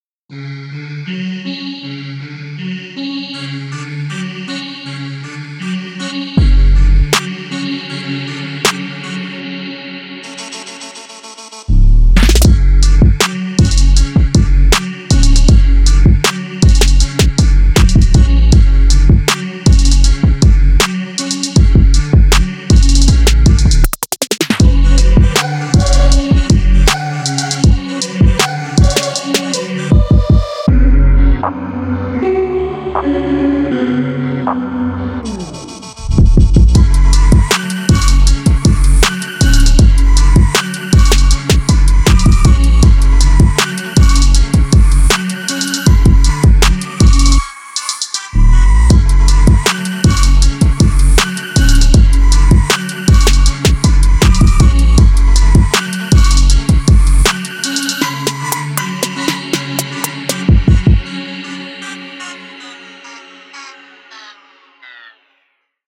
MELODIC HUMAN TEXTURES